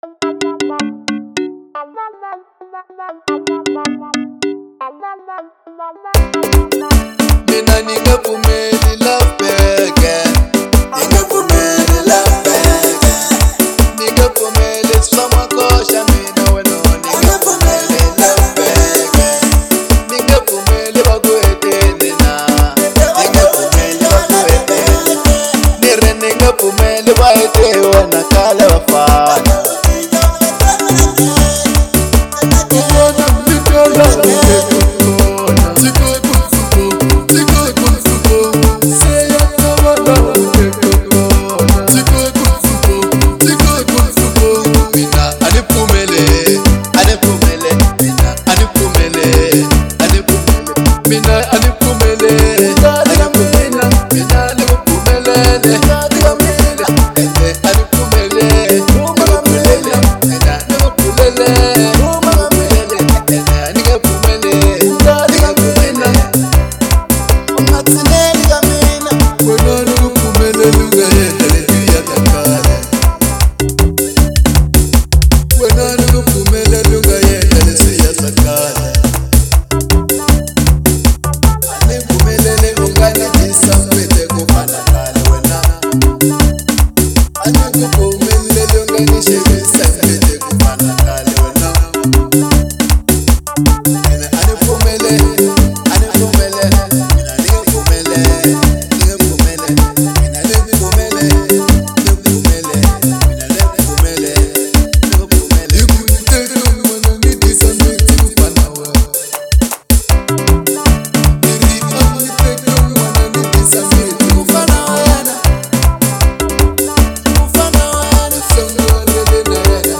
03:15 Genre : Xitsonga Size